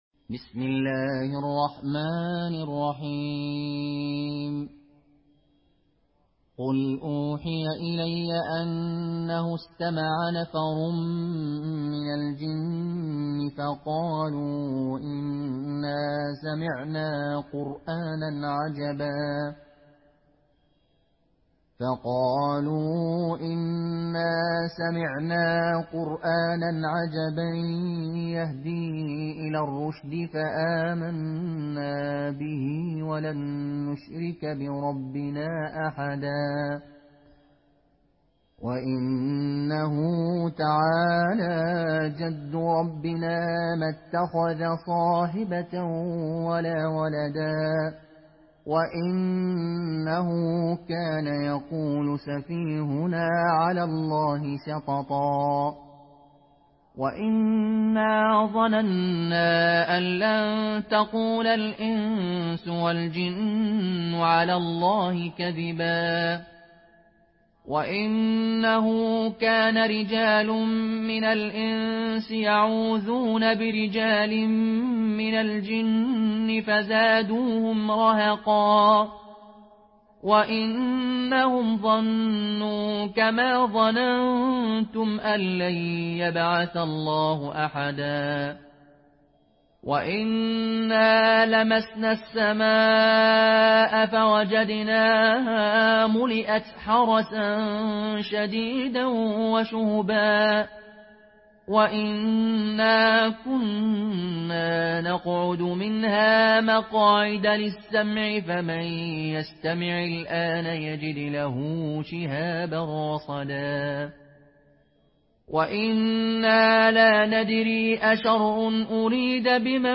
in Qaloon Narration
Murattal